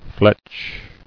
[fletch]